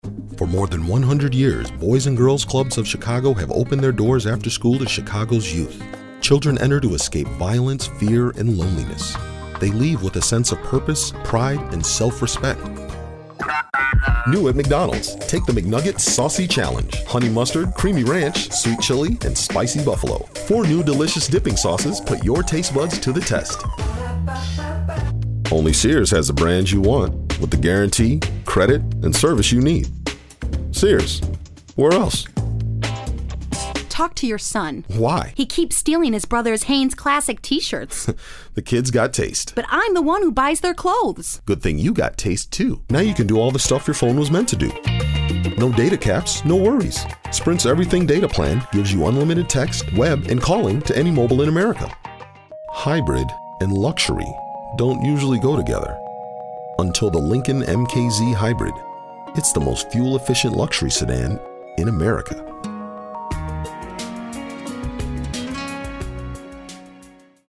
Sprechprobe: Werbung (Muttersprache):
voice is a strong baritone with highly authoritative and persuasive characteristics. It’s a sound that embodies confidence and power, with tones that are warm and inviting.
His versatility enables him to smoothly transition from an unaccented middle-American feel to roles requiring an urban contemporary voice. His read is very well-suited for the requirements of corporate and professional work, but when called for, he can display a relaxed conversational style and a natural sense of humor.